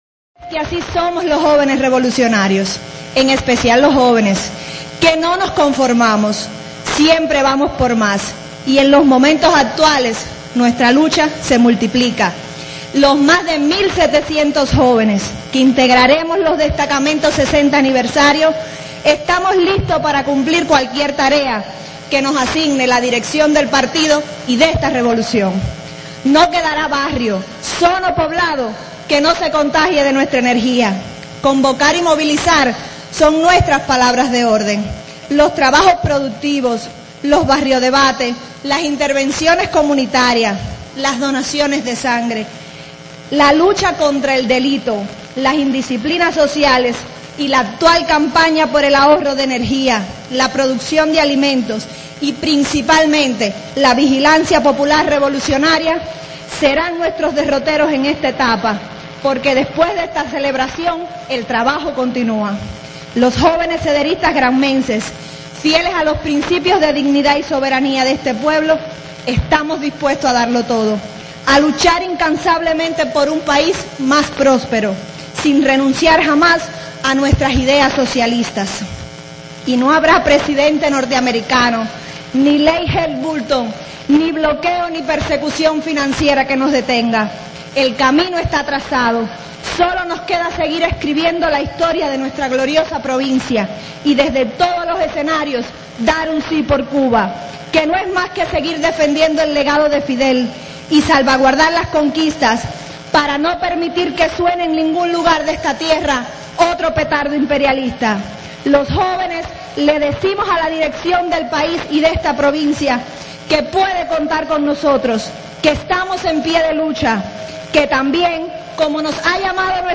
La Plaza del Himno, de Bayamo, donde se entonó públicamente por primera vez el canto patriótico de guerra, acoge hoy alrededor de tres mil granmenses para celebrar el acto central por el aniversario 59 de los Comités de Defensa de la Revolución.
Acto-central-por-el-aniversario-59-de-los-CDR.mp3